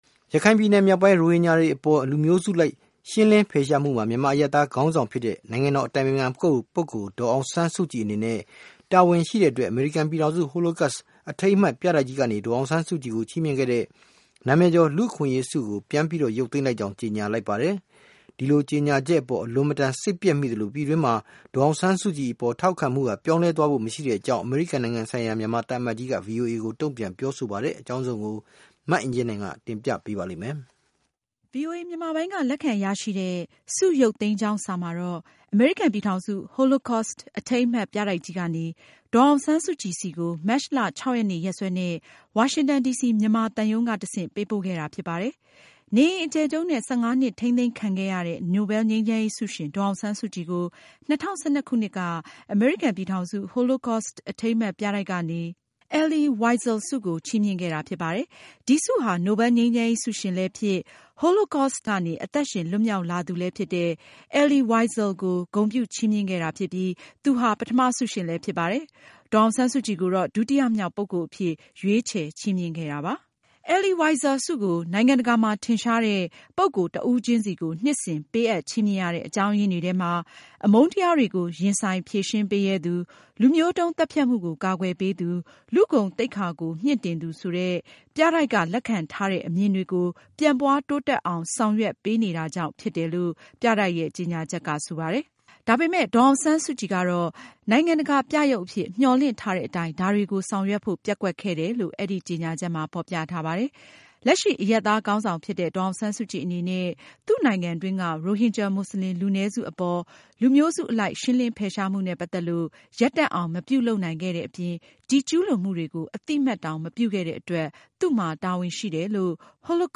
၂၀၁၈ နိုင်ငံတကာ အမျိုးသမီးများနေ့ ကုလအတွင်းရေးမှူးချုပ် အမှာစကား